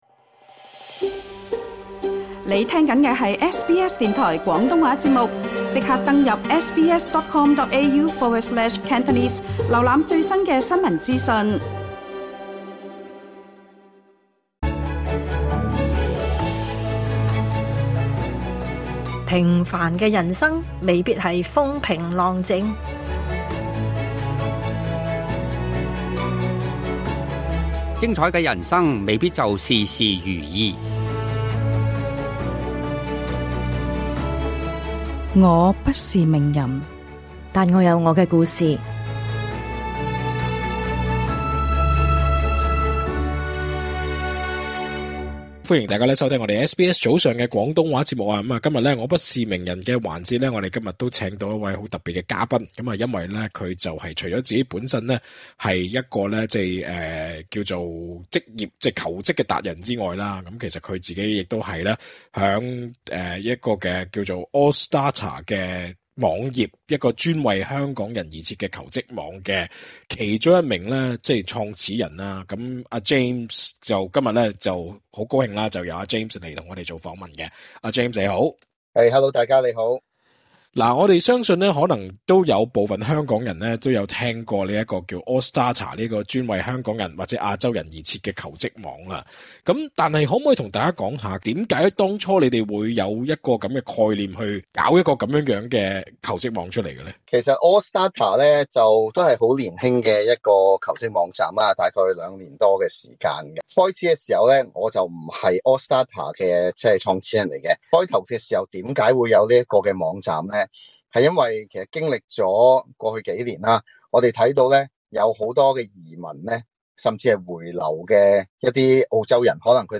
足本訪問請留意本台錄音。